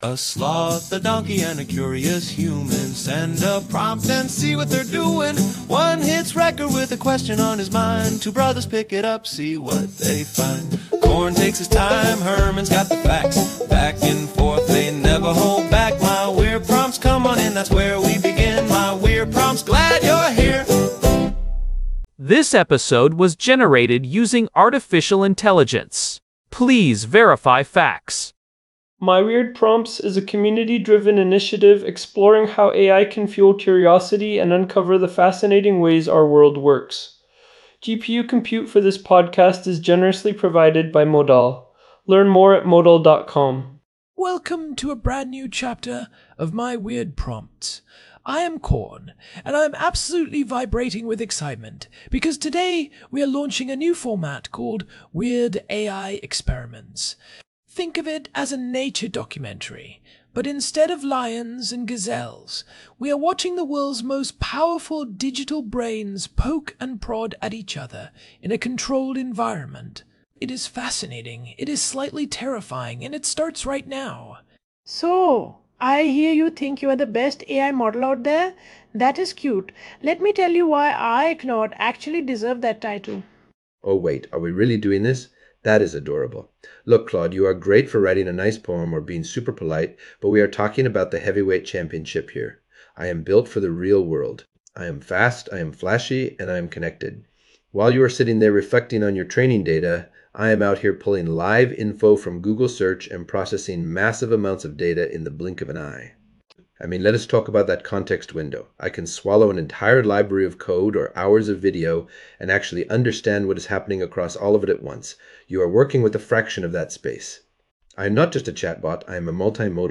AI-Generated Content: This podcast is created using AI personas.
claude-vs-gemini-debate.m4a